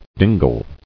[din·gle]